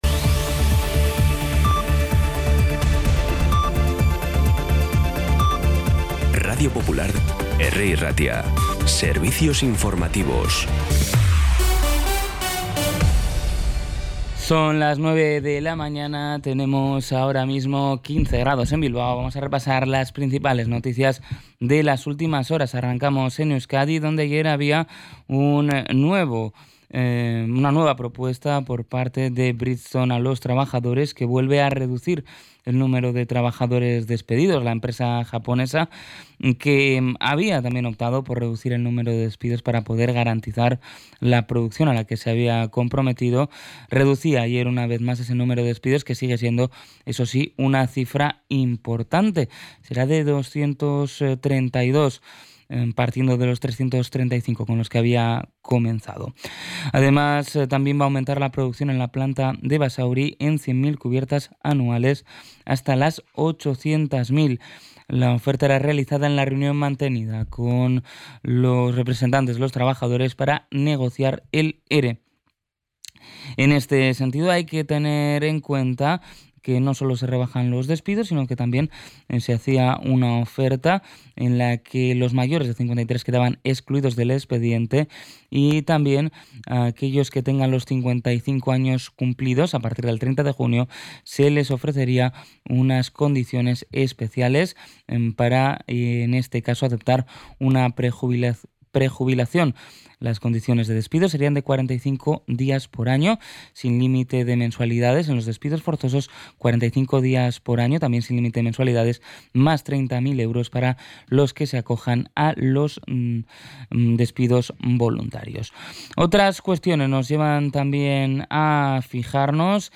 Las noticias de Bilbao y Bizkaia del 13 de mayo las 9
Los titulares actualizados con las voces del día.